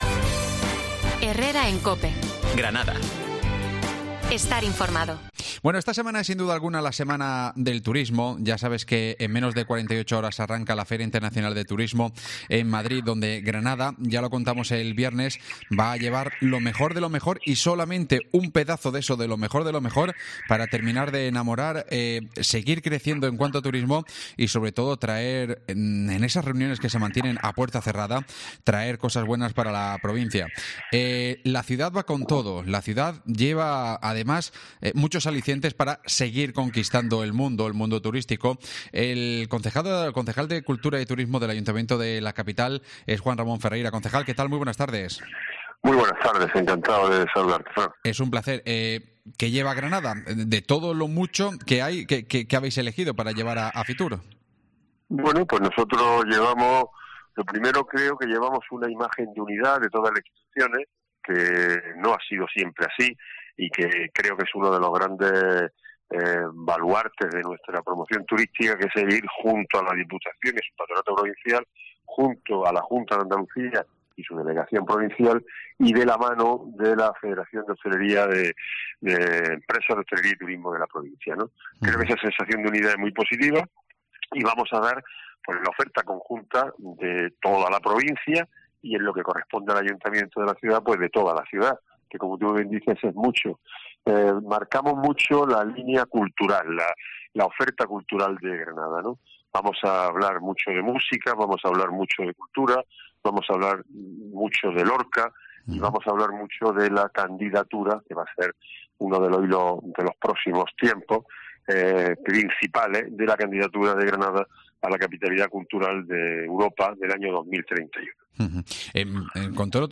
Las detalla el concejal de turismo, Juan Ramón Ferreira en COPE